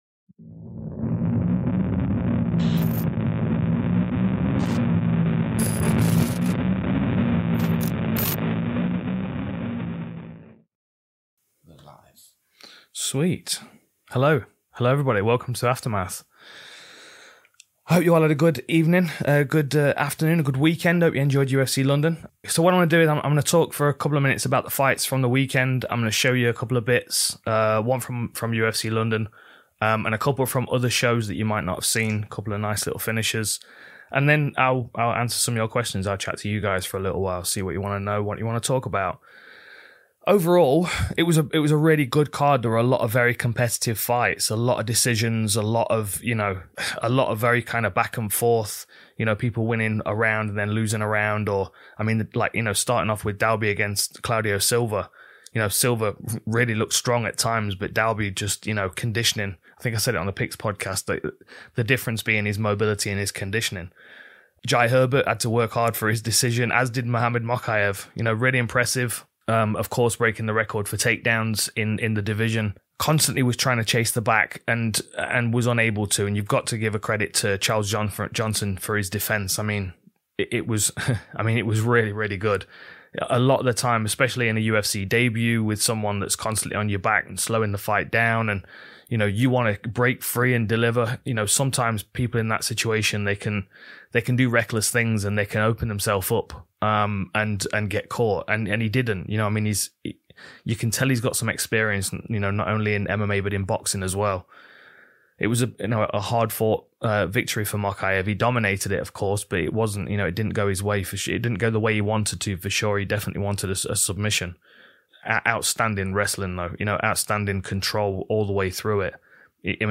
UFC London Aftermath with Dan Hardy | LIVE